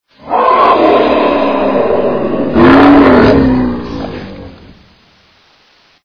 Звуки хищника
На этой странице собраны звуки хищников: рычание, вой, крики и другие устрашающие аудио.
Рык хищного зверя